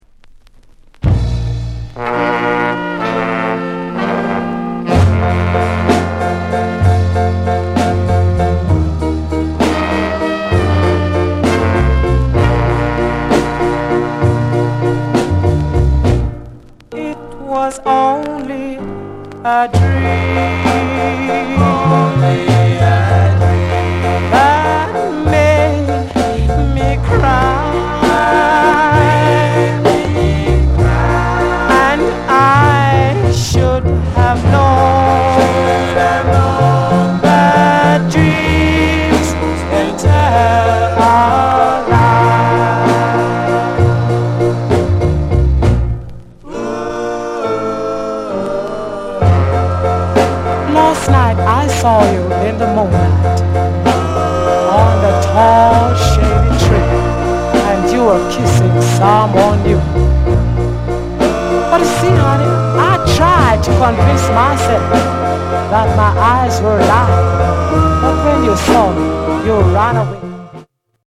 SKA